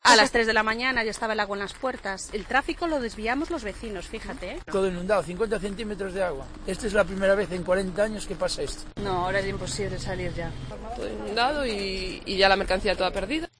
Los vecinos de Sada (A Coruña) relatan cómo están viviendo las inundaciones.